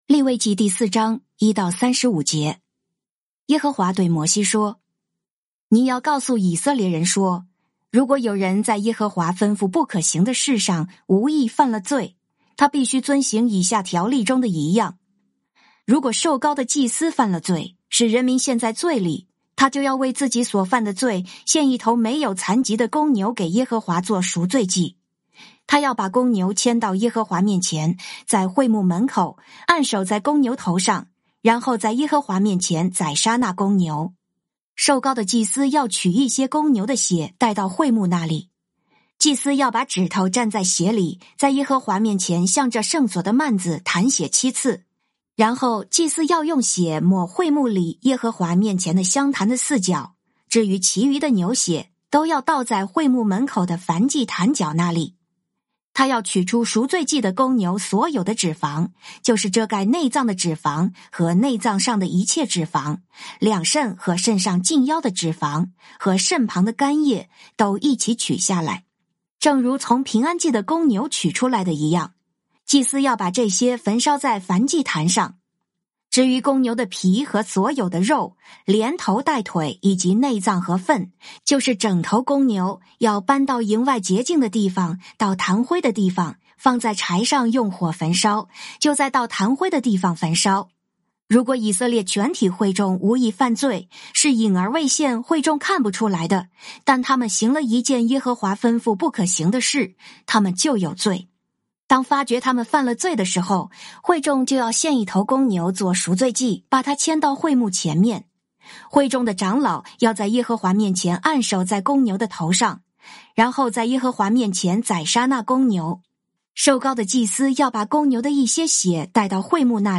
「天父爸爸說話網」是由北美前進教會Forward Church 所製作的多單元基督教靈修音頻節目。